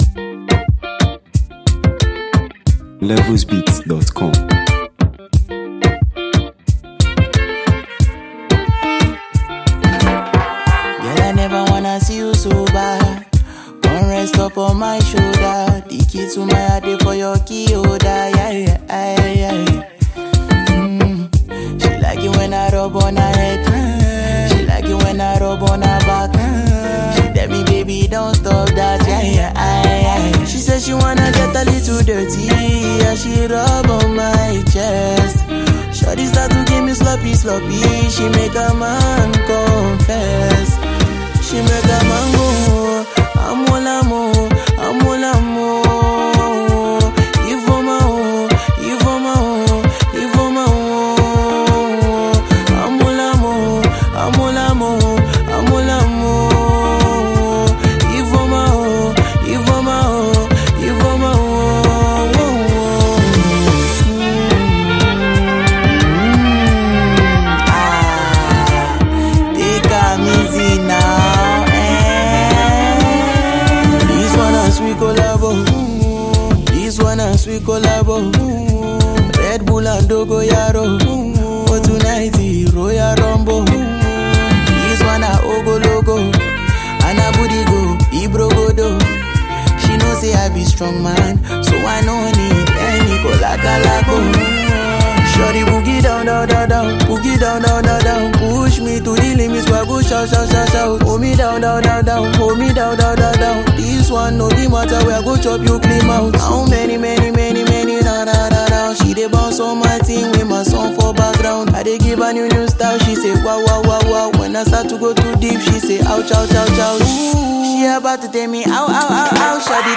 bold and energetic track